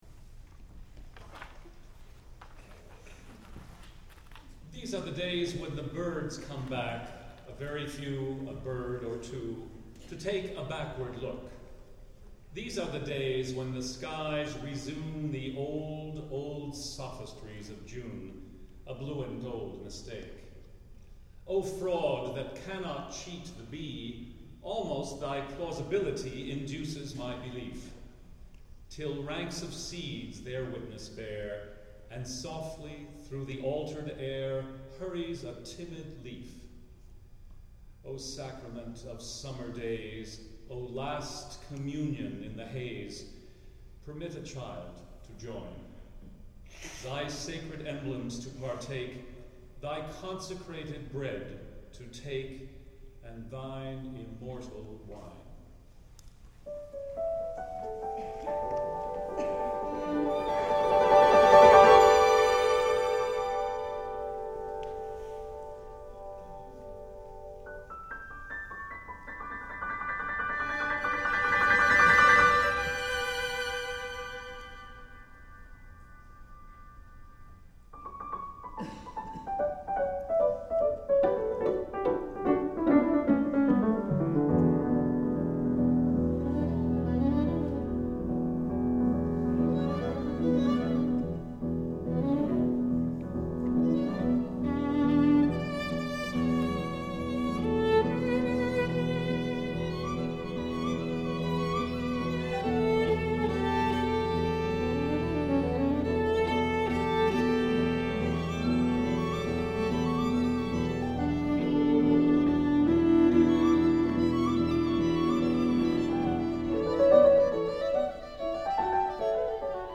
for Piano Quartet (2001)